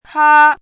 怎么读
[ hā ]
ha1.mp3